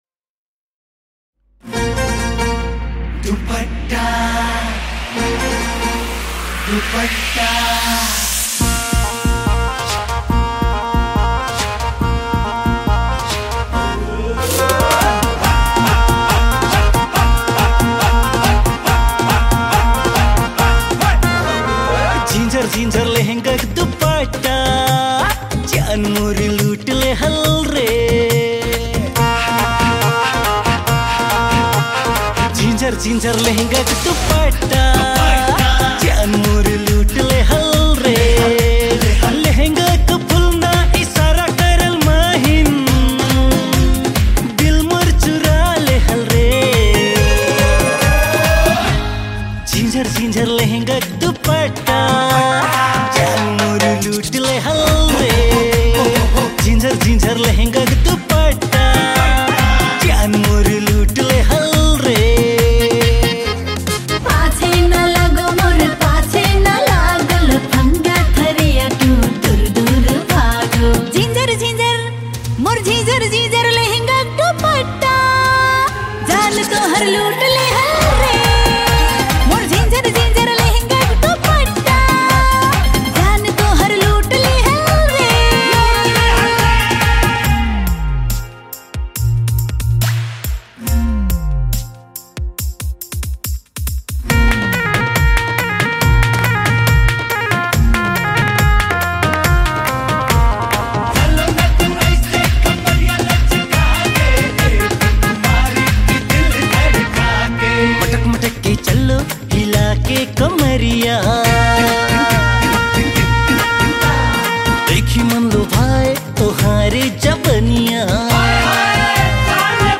New Tharu Song 2023
Tharu Item Dancing Song